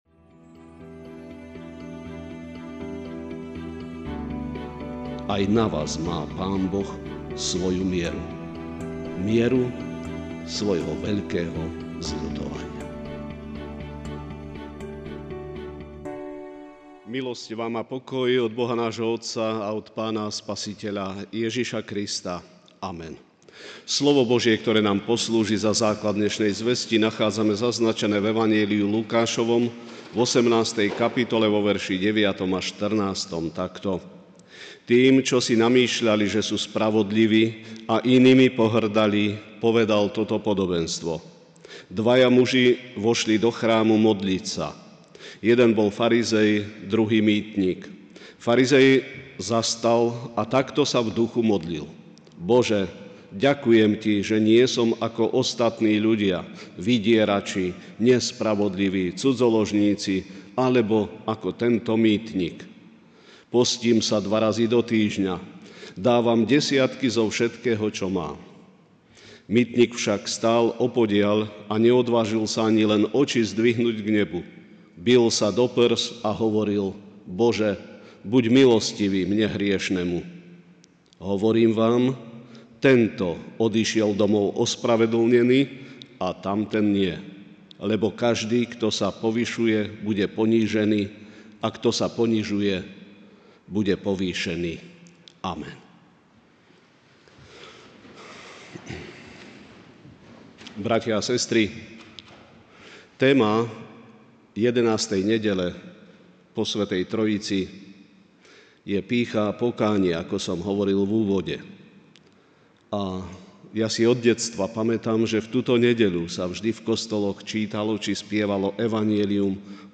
Večerná kázeň: Pýcha pred Bohom (Lukáš 18, 9 -14)